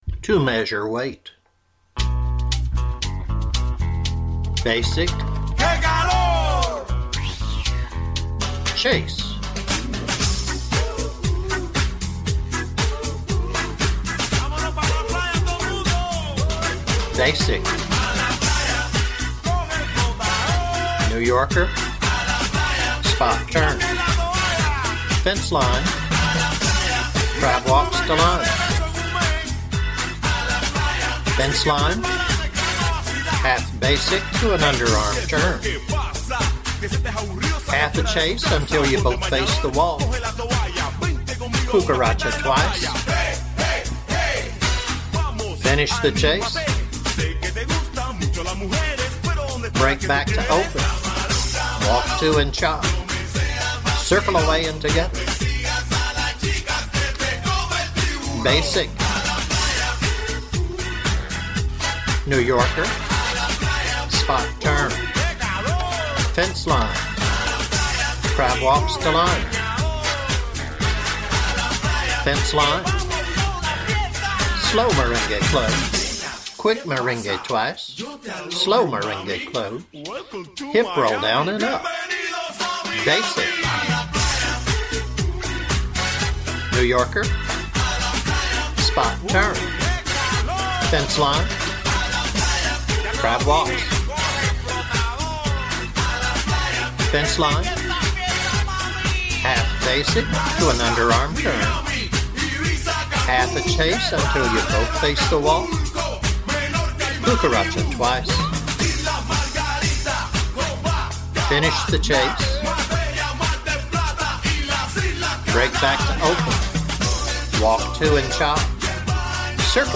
Cha Cha -----------